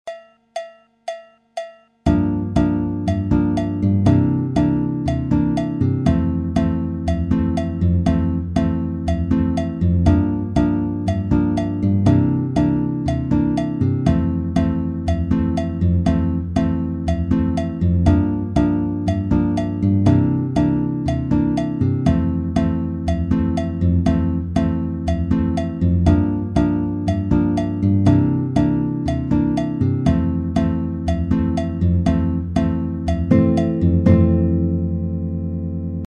Comme support de rythme j'ai mis un clic à la croche, c'est à dire 2 pulsations par temps et cela dans le but de bien décortiquer la tourne de guitare.
La batida figure n°4